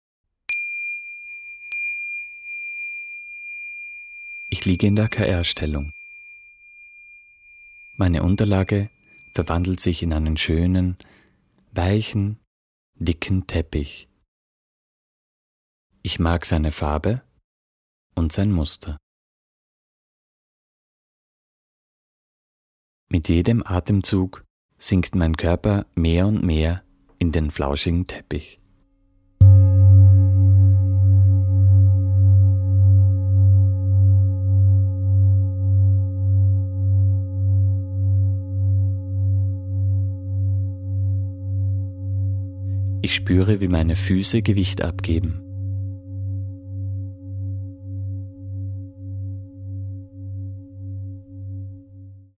Recorded at: Dschungelstudios Vienna, Austria